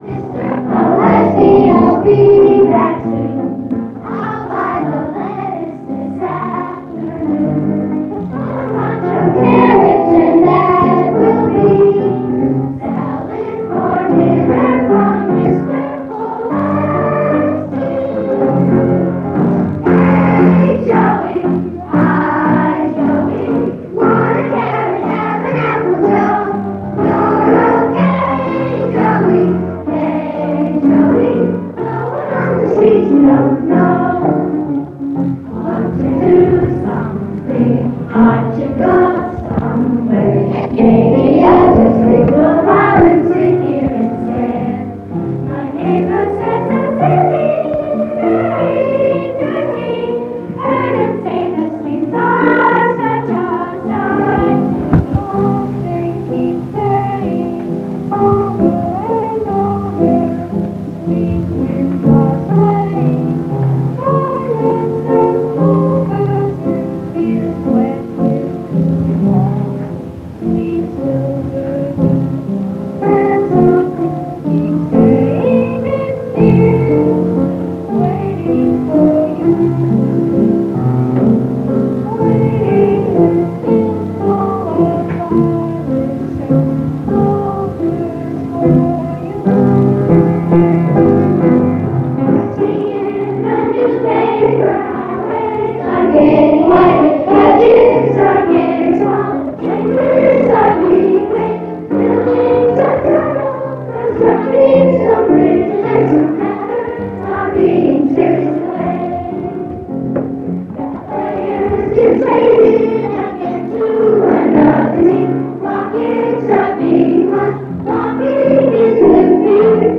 Archival Student Performance Demo